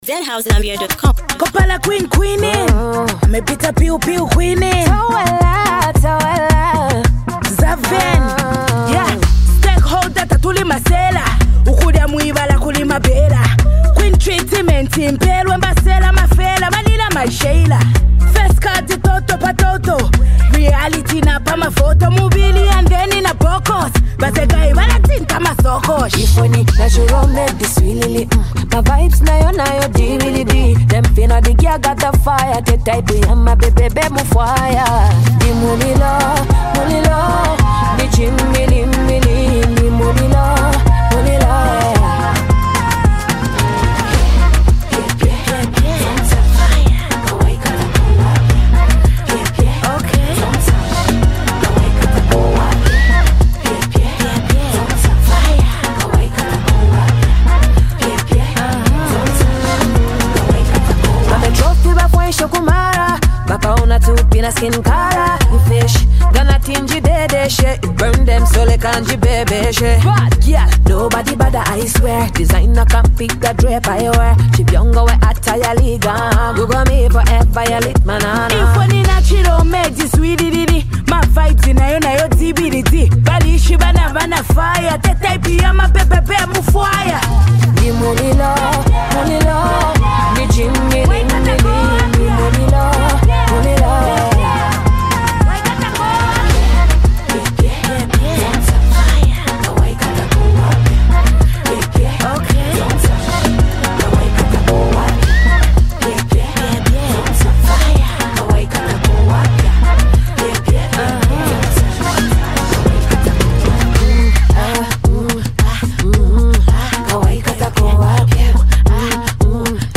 soulful vocals
energetic rap